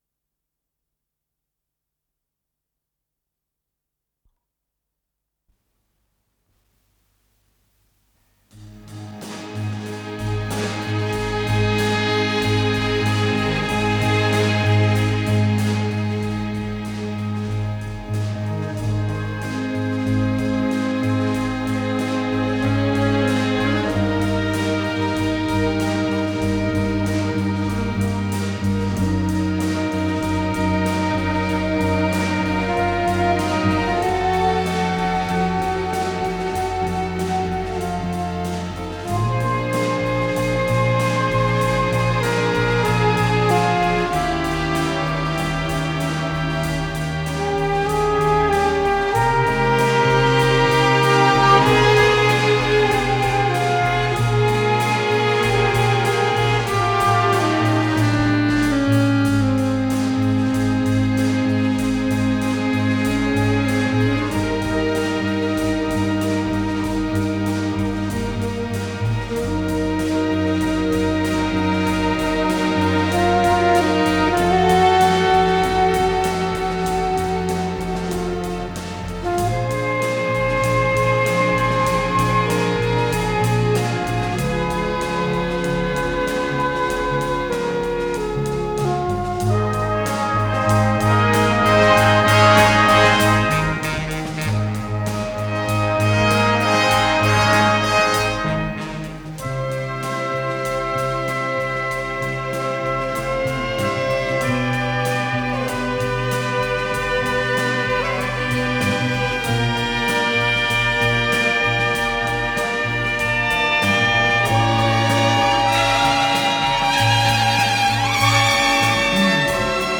ВариантДубль моно